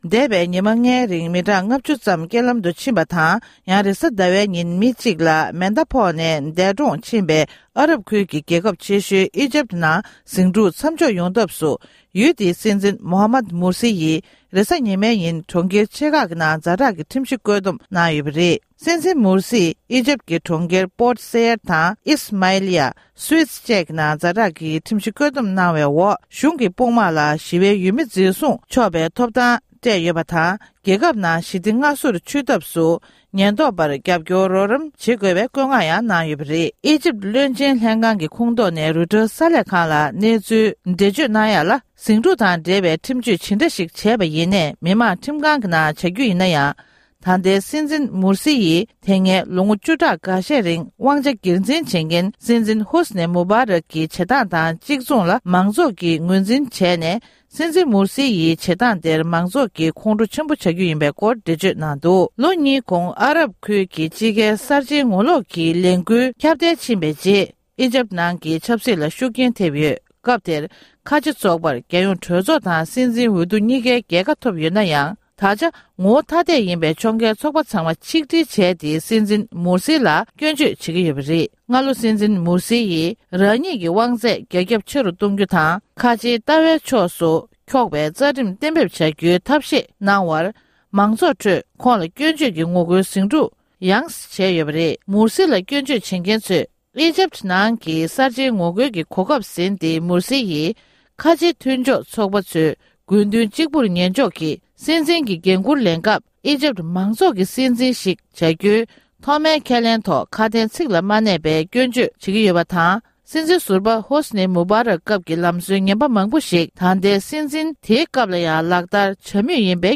སྒྲ་ལྡན་གསར་འགྱུར།
གསར་འགྱུར་དཔྱད་གཏམ།